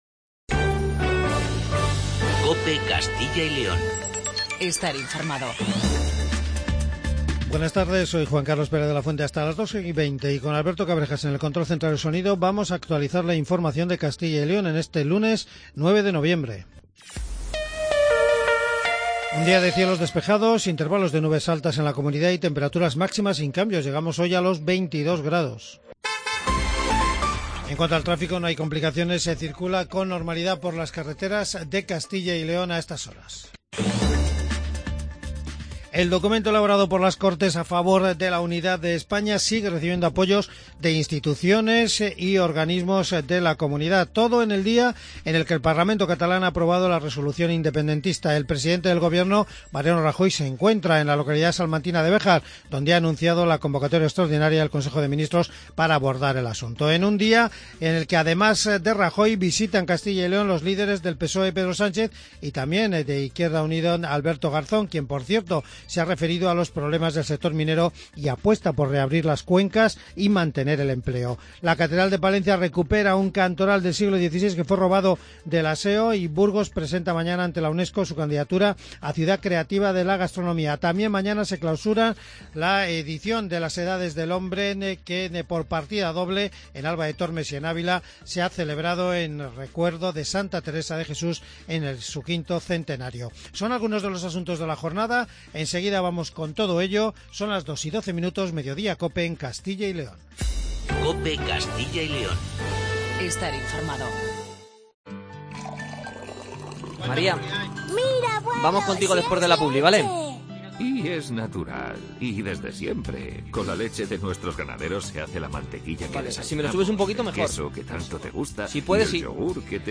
AUDIO: Informativo regional